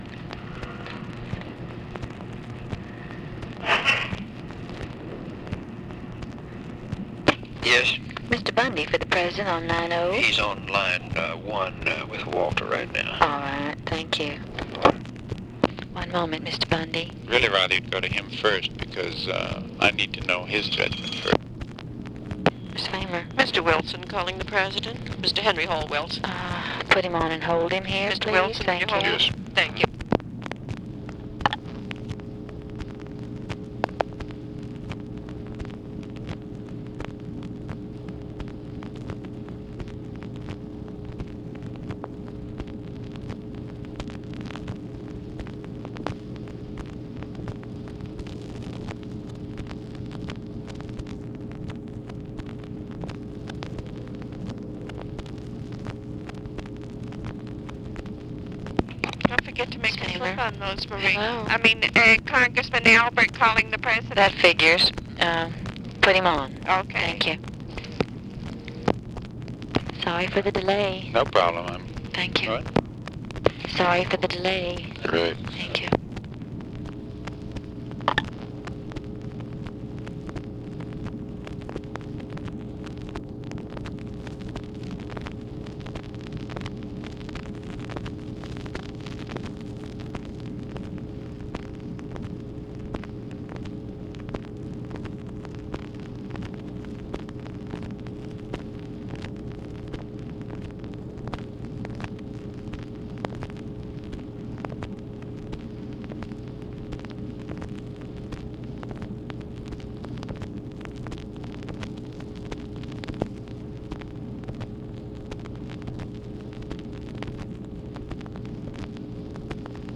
Conversation with VARIOUS SPEAKERS
Secret White House Tapes